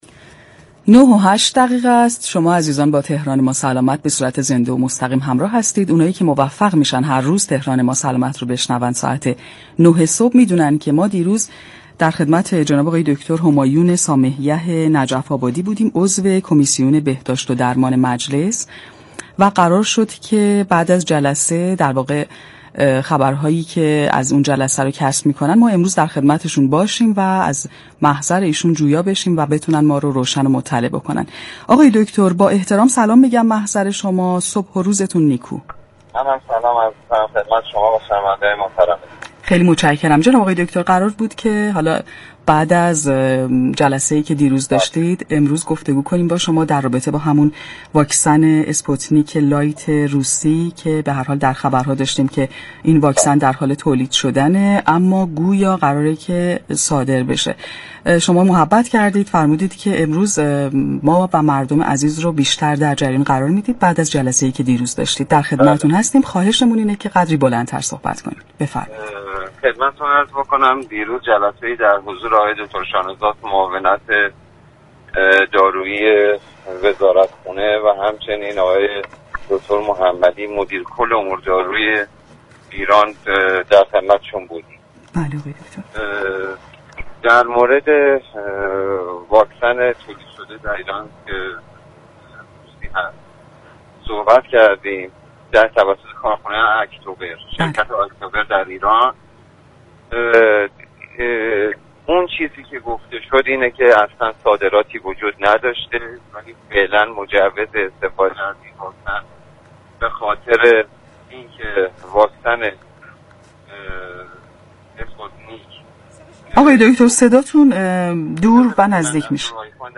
به گزارش پایگاه اطلاع رسانی رادیو تهران، دكتر همایون سامه یح نجف آبادی، نماینده مجلس یازدهم در گفتگو با برنامه تهران ما سلامت یكشنبه 14 شهریورماه رادیو تهران درباره حواشی تولید واكسن اسپوتنیك روسیه در ایران توسط شركت اكتور گفت: اظهارات متناقضی در چند روز اخیر توسط شركت اكتور ایران در راستای تولید واكسن اسپوتنیك ارائه شده است.